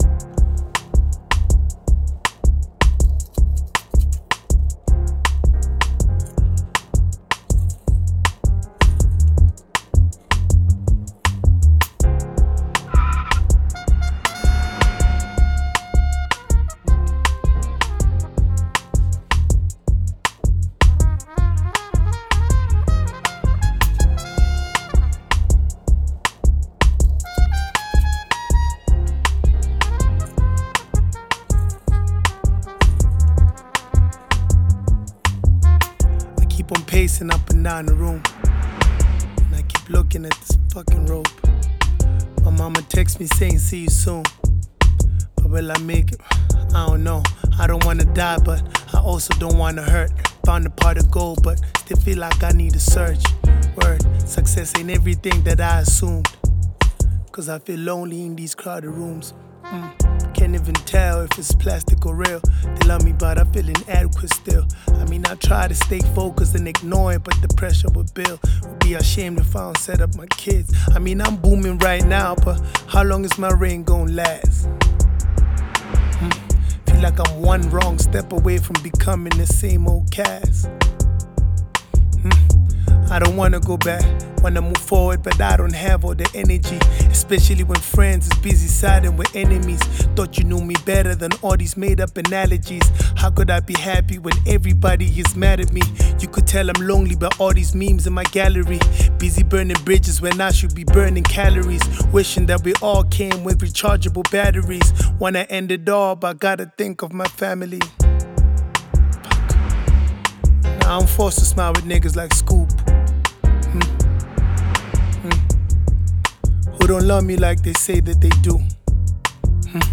South African rapper
American rapper and songwriter